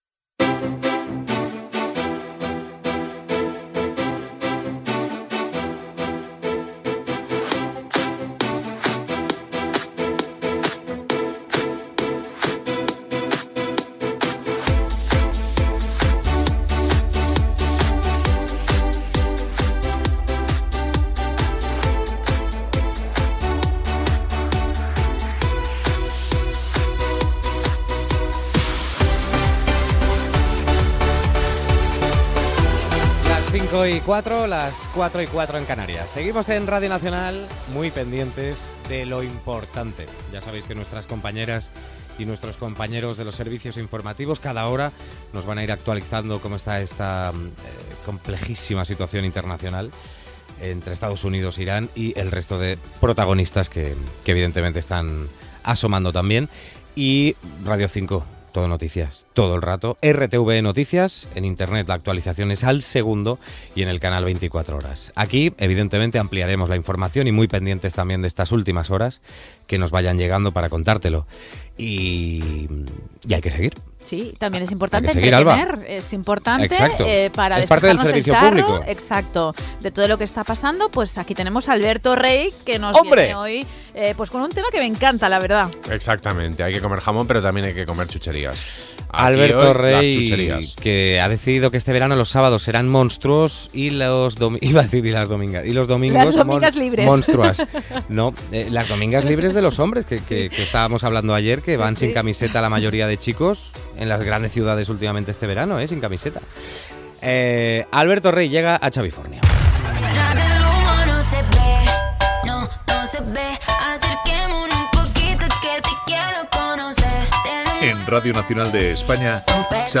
Sintonia del programa, hora, comentari sobre els mitjans informatius de RTVE, indicatiu, diàleg entre l'equip, dones famoses que tenen marques i empreses de cosmètica
Entreteniment